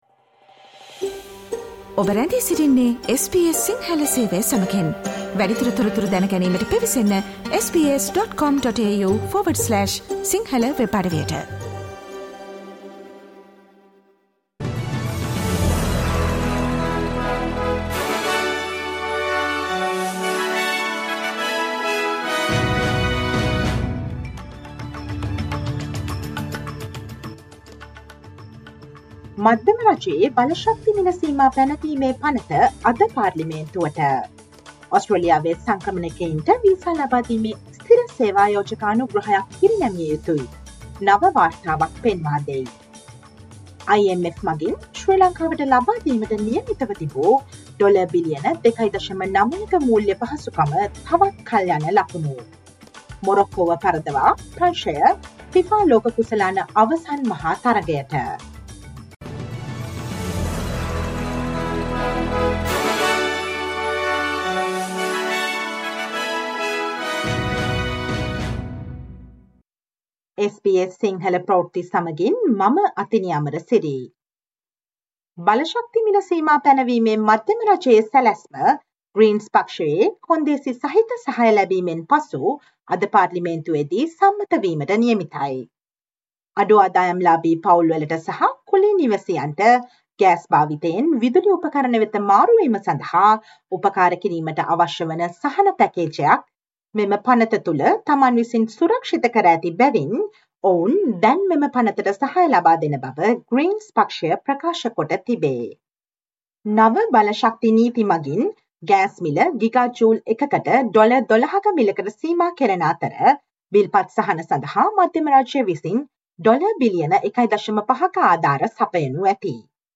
Listen to the SBS Sinhala Radio news bulletin on Thursday December 2022